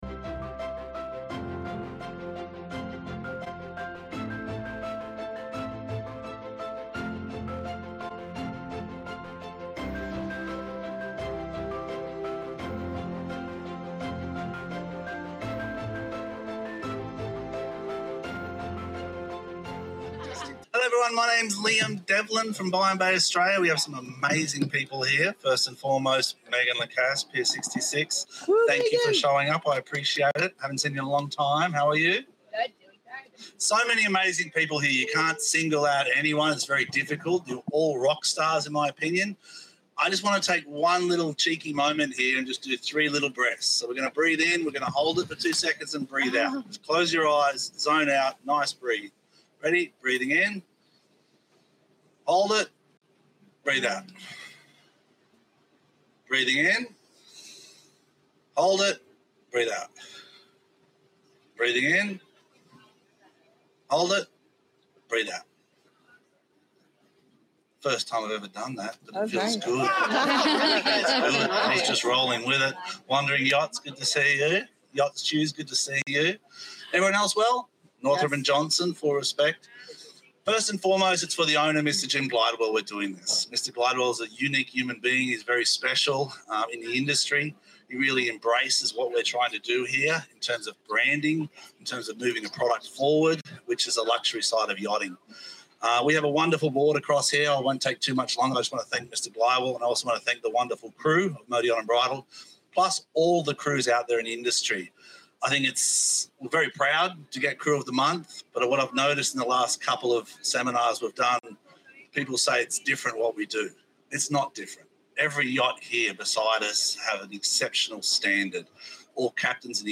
Dive into a powerful conversation on the evolving role of women in yachting